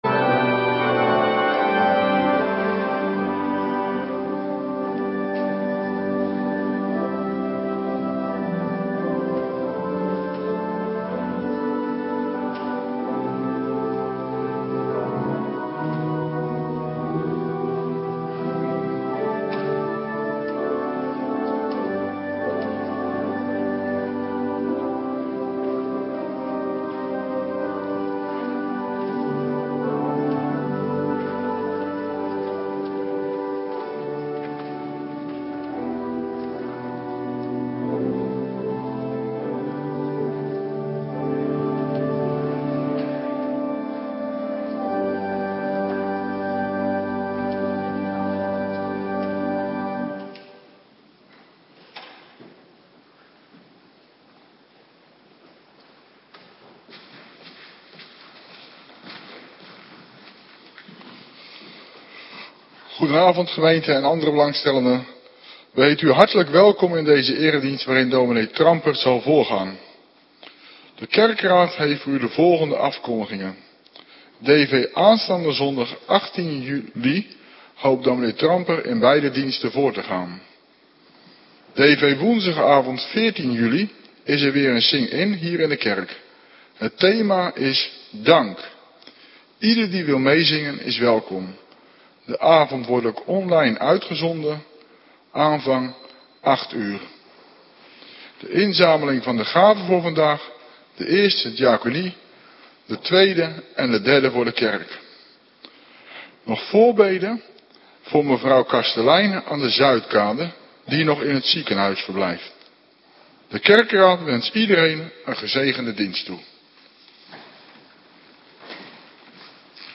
Avonddienst - Cluster A
Locatie: Hervormde Gemeente Waarder